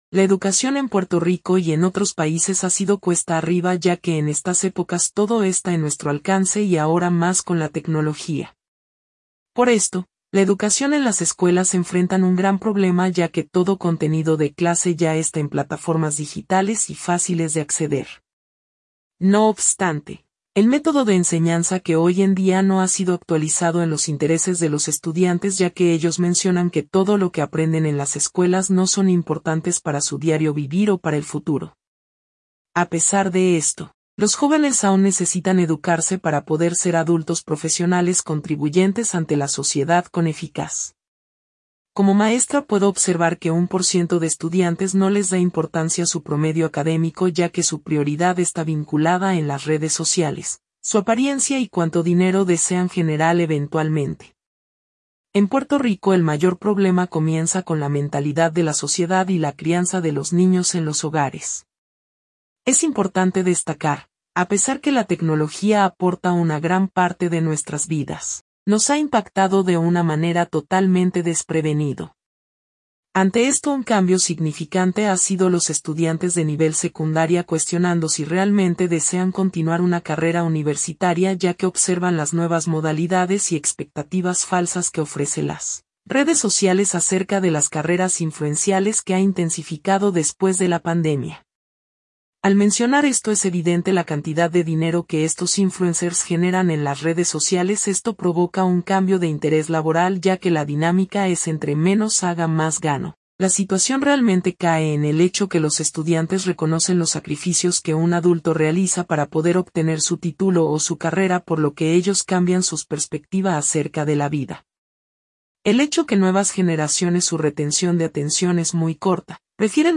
Presenta audio de maestra de Puerto Rico sobre su ecperiencia con estudiantes que no les motiva estudiar.